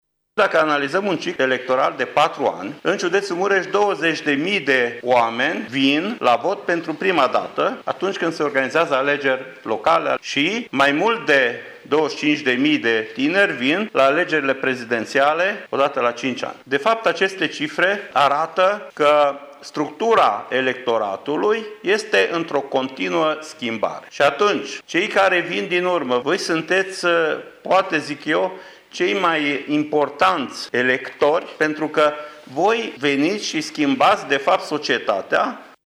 Cu o oarecare întârziere din cauza vacanţei şcolare, Autoritatea Electorală Permanentă a organizat astăzi, la Universitatea „Petru Maior” din Tîrgu-Mureş, un workshop.
Prezent la eveniment, inspectorul şcolar general al judeţului Mureş, Ştefan Someşan, a explicat că numărul tinerilor care merg la vot pentru prima dată într-un ciclu electoral este de 20.000: